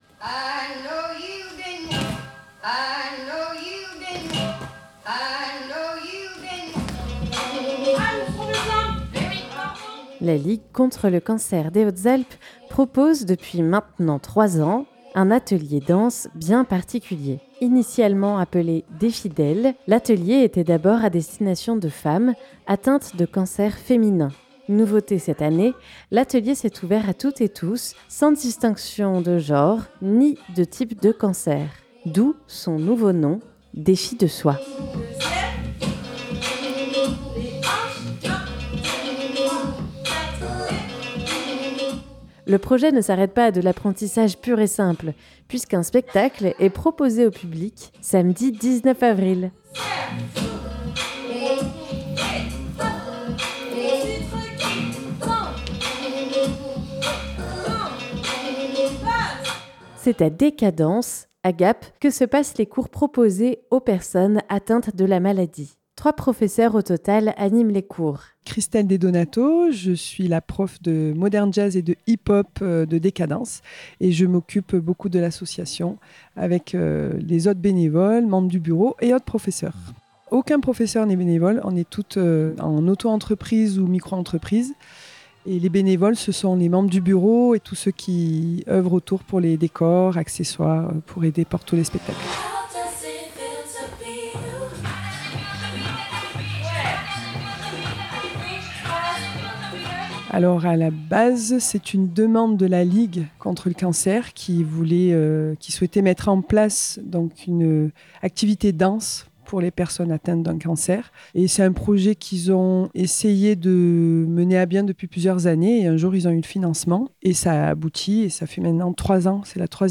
Fréquence Mistral a pu assister à une des répétitions